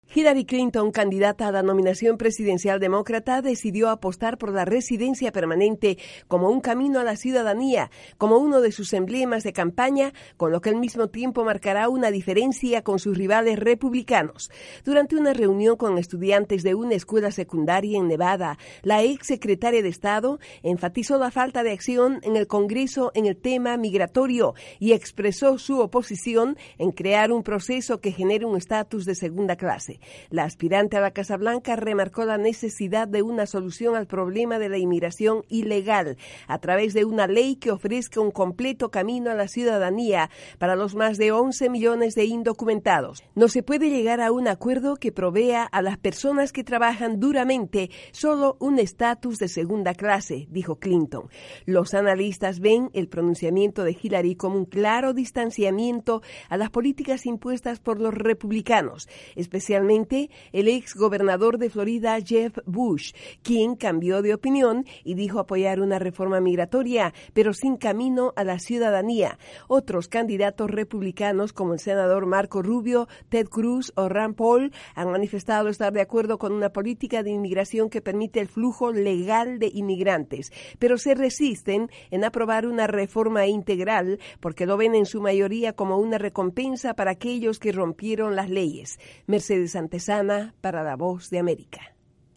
La candidata presidencial demócrata Hillary Clinton apuesta a la reforma migratoria en EE.UU. Desde la Voz de América en Washington informa